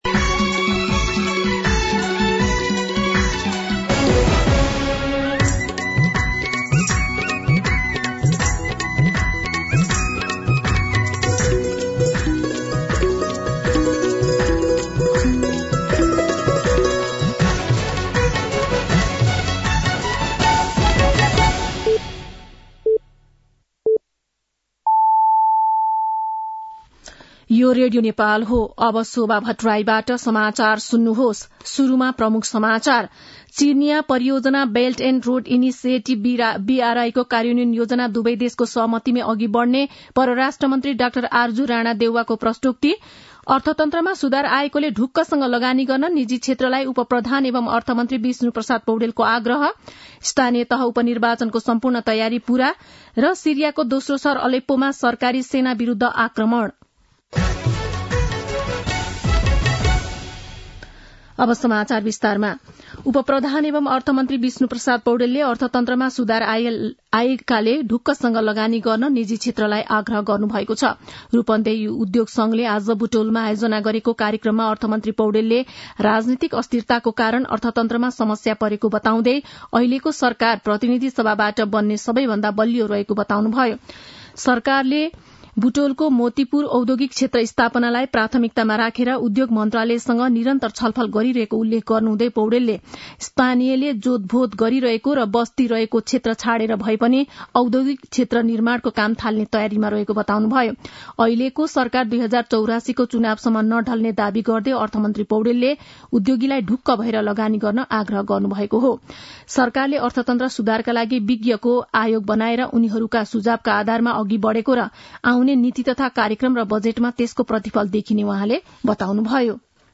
दिउँसो ३ बजेको नेपाली समाचार : १६ मंसिर , २०८१
3-pm-nepali-news-1-10.mp3